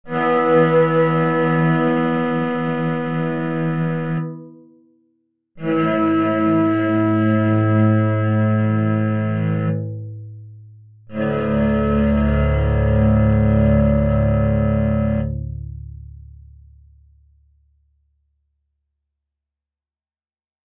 This page contains some example sounds produced by Tao instruments.
A bowed stringed instrument
• This instrument is deceptively simple to look at, but the sounds it produces are remarkably cello-like.
Each string is tuned a fifth higher than the previous, hence the diminishing lengths, and they are bowed in pairs with Bow devices.
It also ensures that the bowing action produces clean notes rather than horrible screaching sounds!